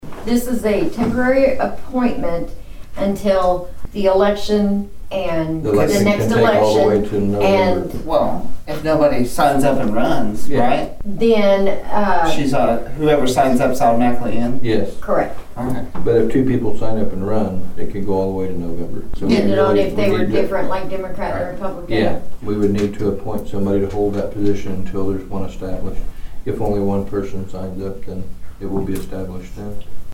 District Three Commissioner Troy Friddle, Vice Chairman Brandon Wesson and County Clerk Kay Spurgeon discussed the process of a temporary treasurer.